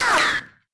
auto_flyby3.wav